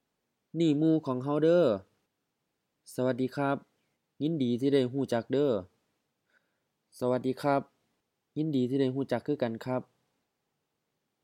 BCF03 Introducing someone else to others — Dialogue A